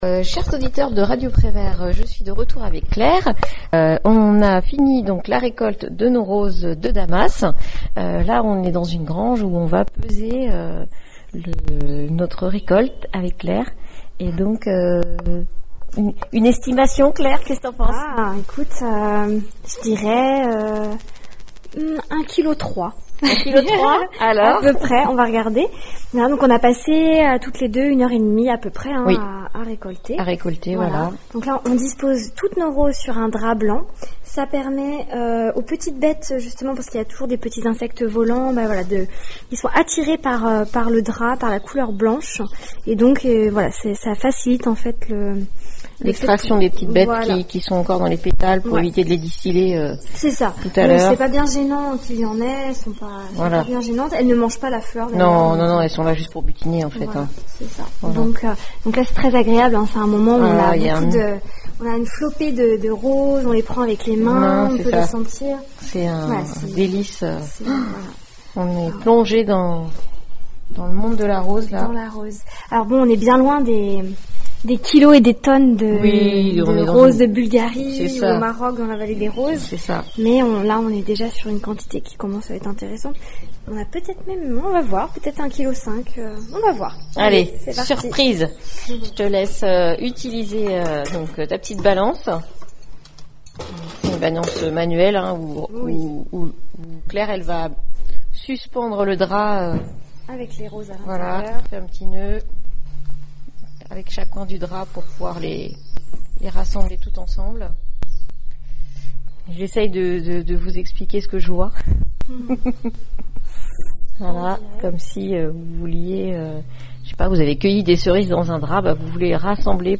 reportage au Piton du Dehors à Mont-Saint-Jean